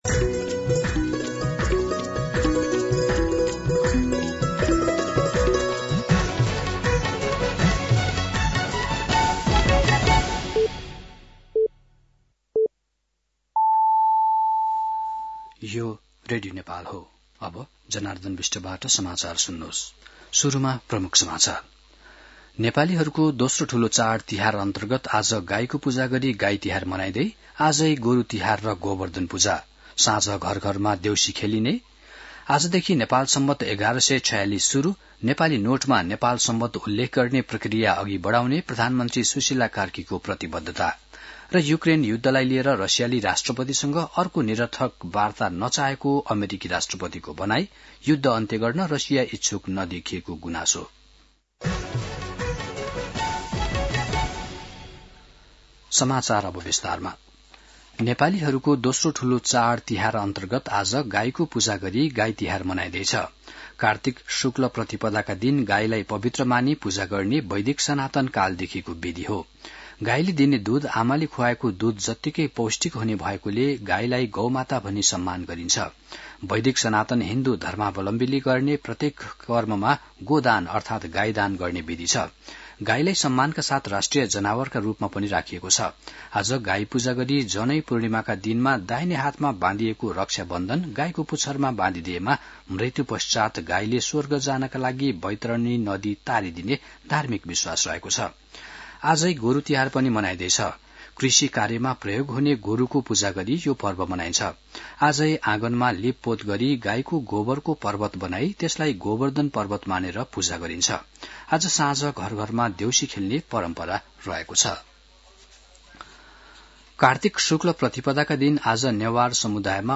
दिउँसो ३ बजेको नेपाली समाचार : ५ कार्तिक , २०८२
3pm-News-05.mp3